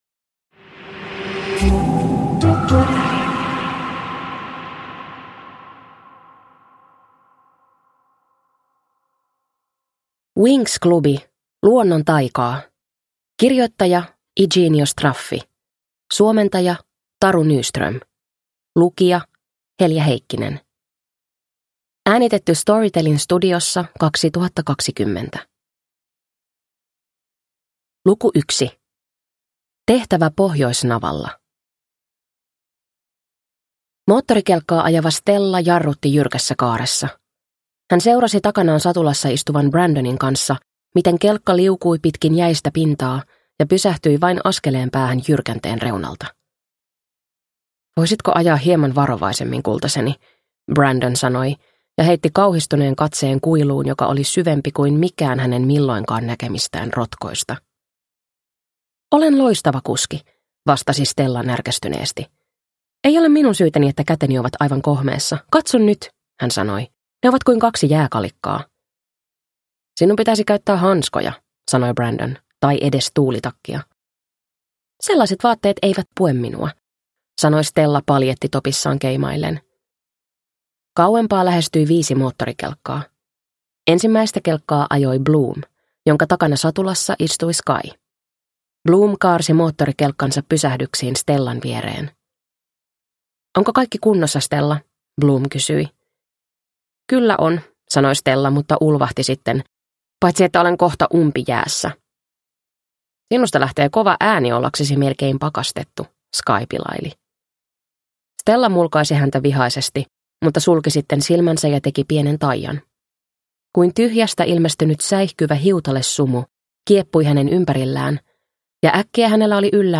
Winx - Luonnon taikaa – Ljudbok